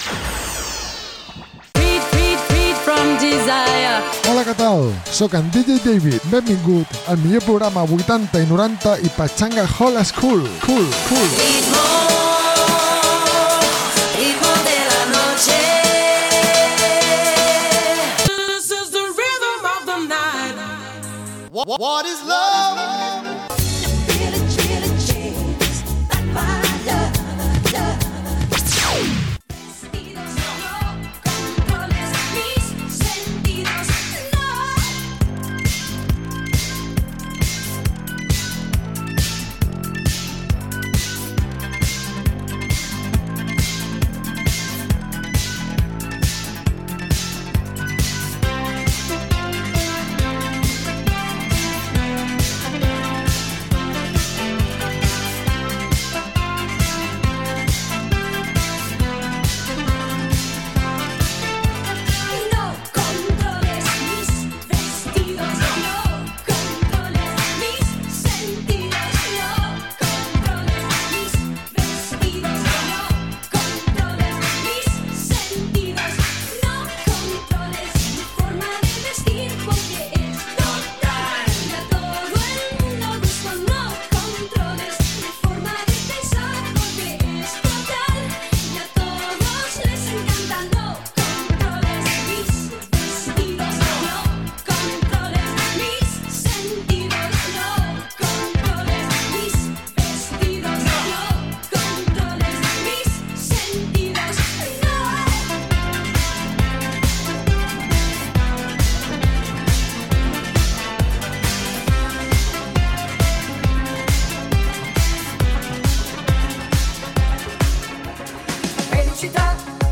remixos classics